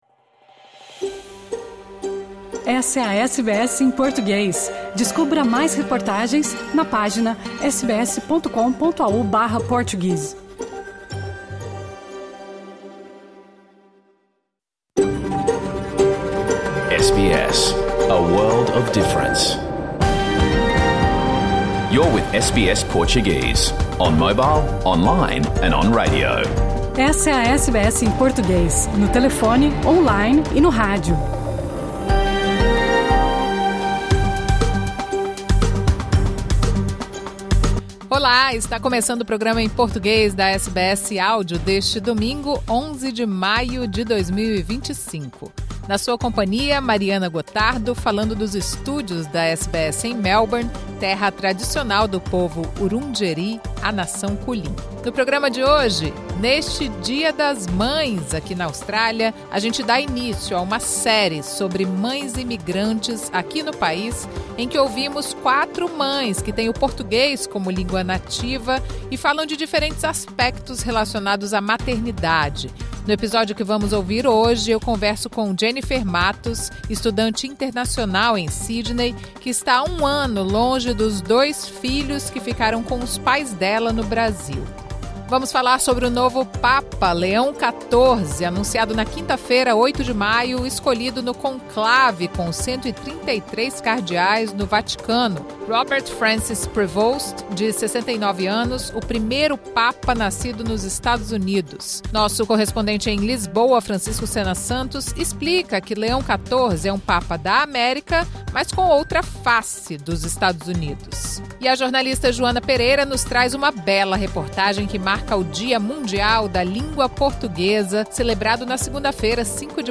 Programa ao vivo | Domingo 11 de maio
Uma reportagem especial da SBS em Português marca o Dia Mundial da Língua Portuguesa e ouve o Embaixador de Portugal na Austrália, António Moniz.